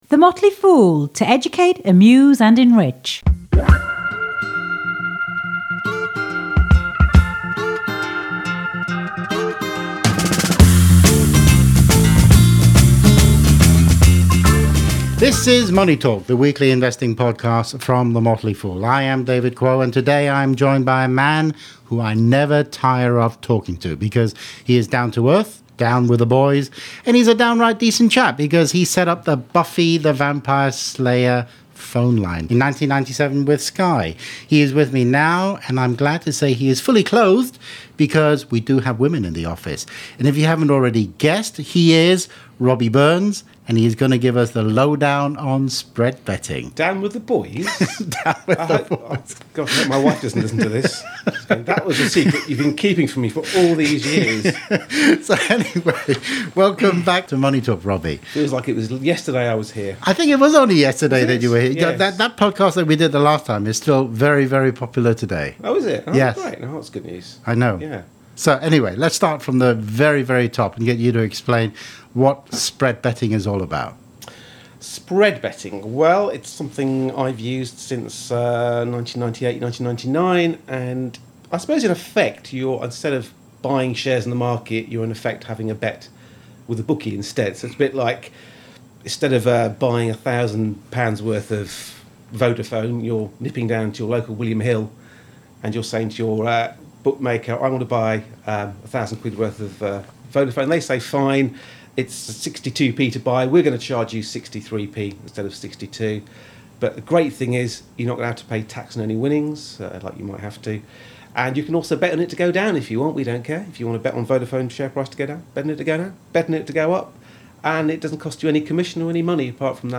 talks